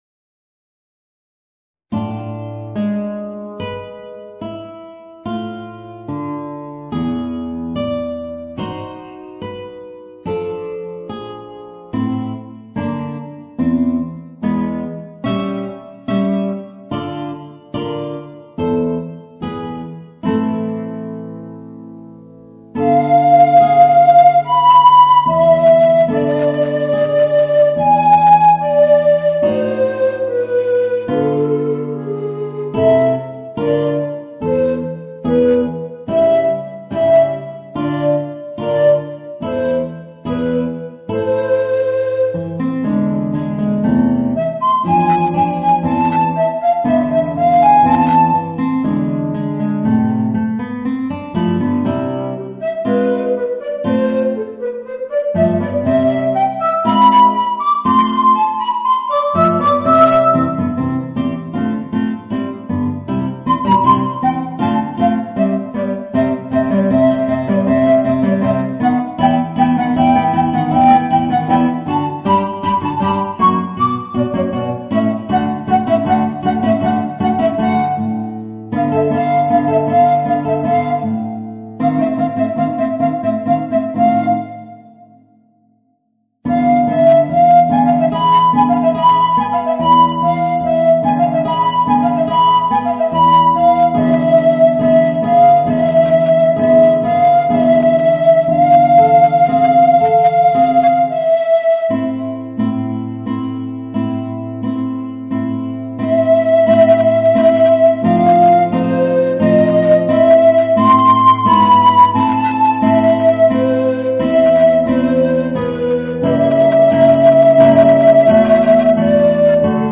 Voicing: Guitar and Alto Recorder